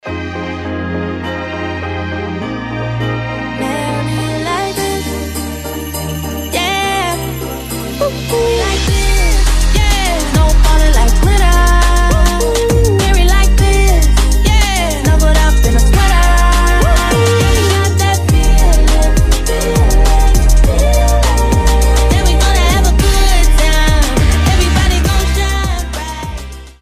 • Качество: 320, Stereo
праздничные
колокольчики
рождественские
соул